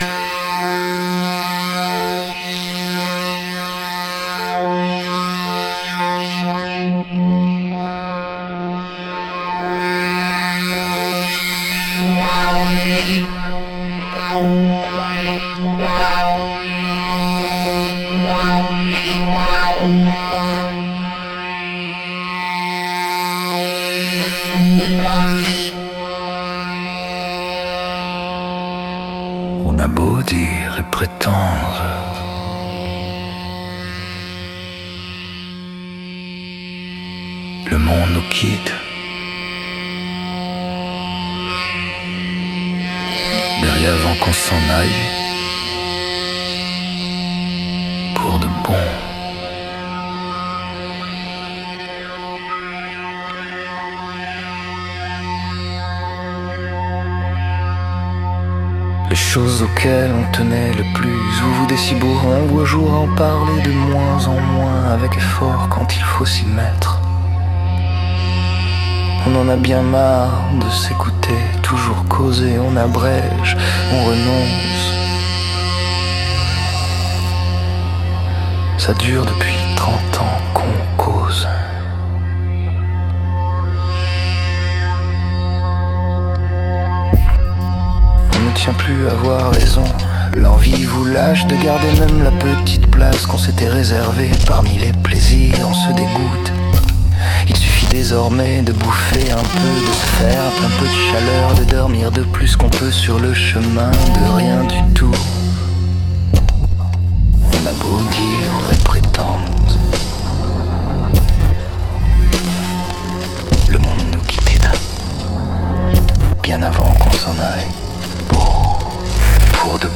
BPM : 100
Caption automatique de suno de la piste de base: The piece is an instrumental track featuring a dark ambient style with elements of industrial sound design. It begins with a sustained, low-frequency drone, creating a sense of tension and unease. This drone is accompanied by metallic, percussive hits that are heavily processed with reverb and delay, giving them a cavernous and expansive quality.